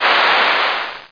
STEAM2.mp3